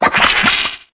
Cri d'Arrozard dans Pokémon HOME.